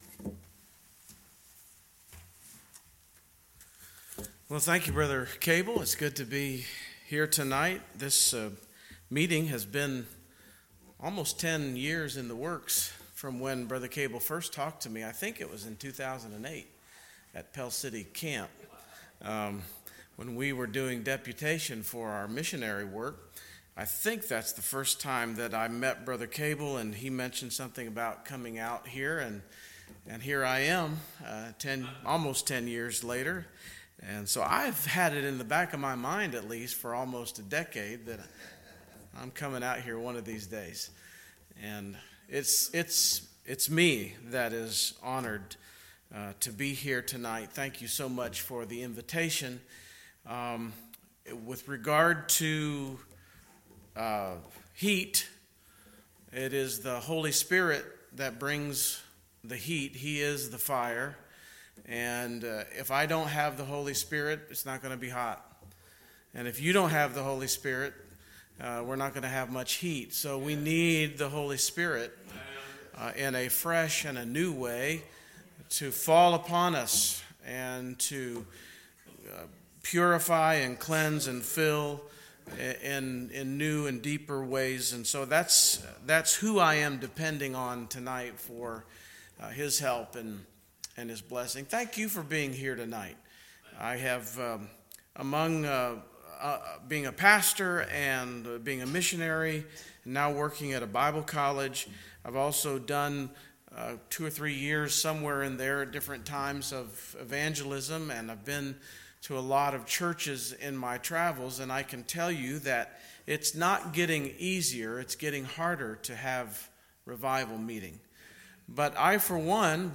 Fall Revival 2017